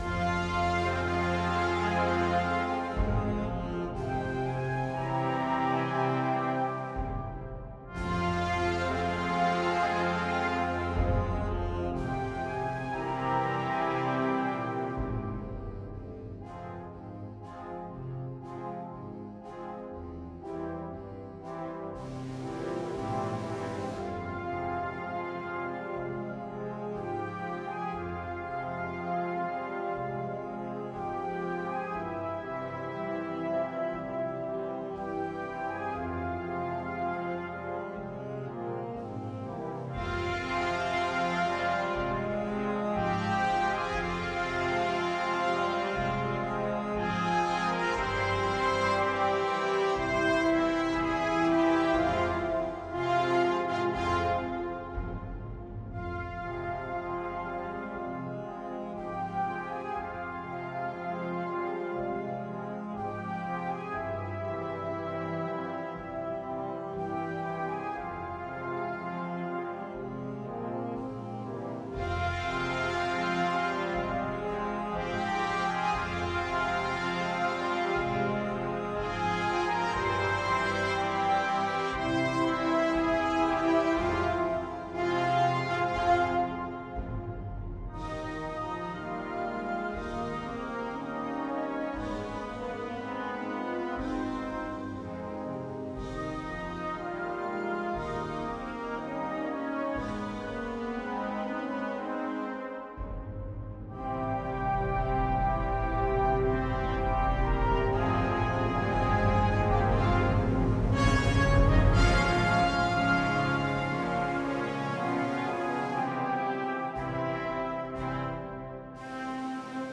Marcia Funebre